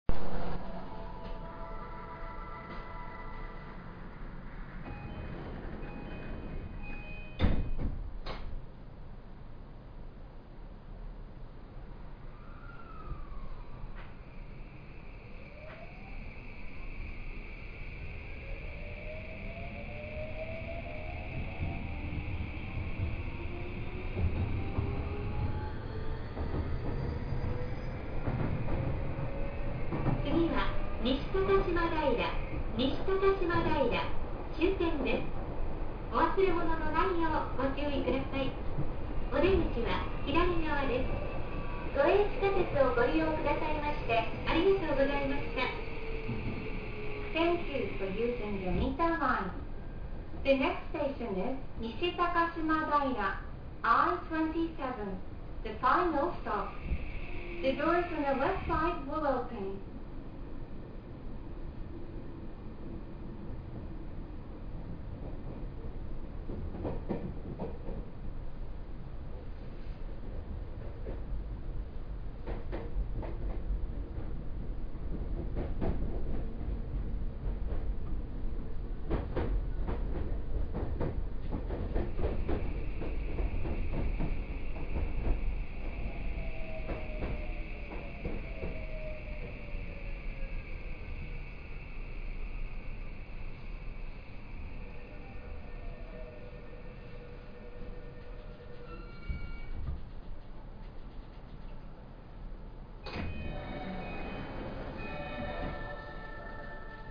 ・3020系走行音
【都営三田線】新高島平→西高島平
2020系・6020系同様の三菱SiCとなっています。というわけで、SiCの中では派手な音を立てる方で聞きごたえはあると言えそうです。
3020_ShinTakashima-NishiTakashima.mp3